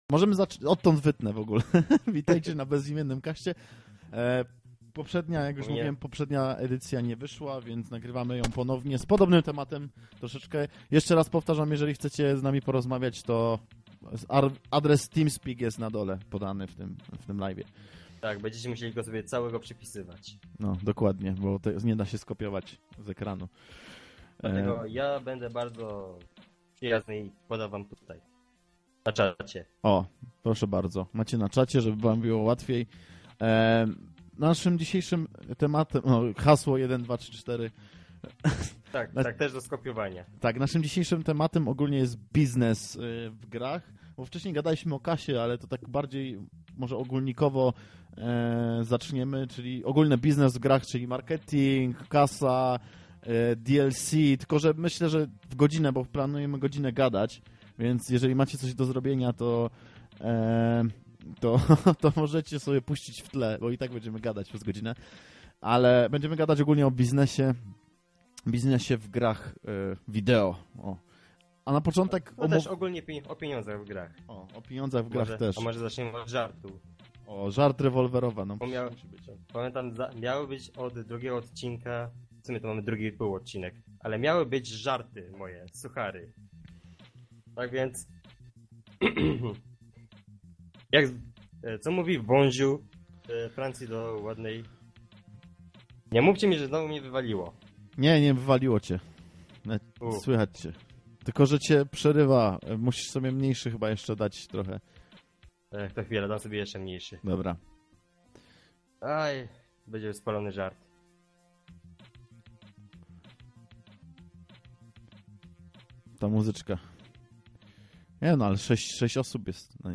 Nasz projekt jest opiera się na luźnej rozmowie na dany temat z zaproszonymi gośćmi w formie audycji/podcastu. W drugim odcinku naszego programu porozmawiamy o grach pod kątem biznesu i pieniędzy.